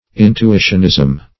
Intuitionism \In`tu*i"tion*ism\, n.